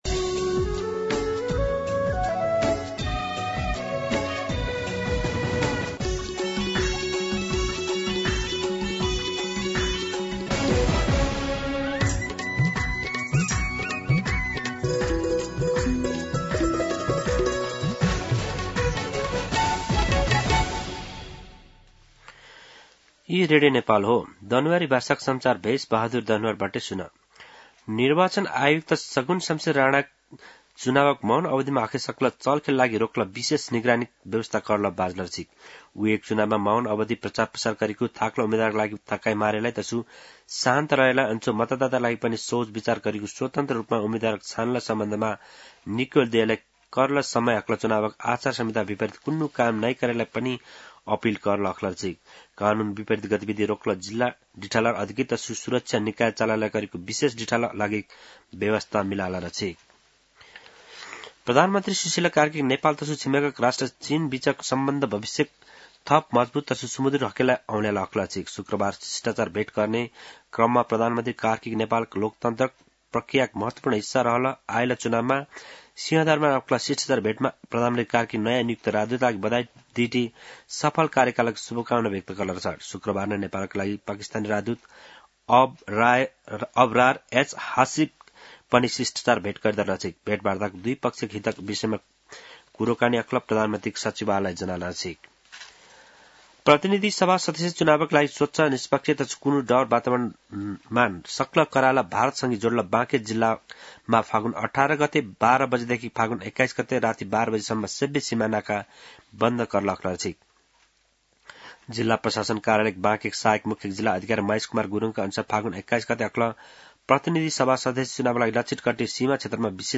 दनुवार भाषामा समाचार : १६ फागुन , २०८२
Danuwar-News-16.mp3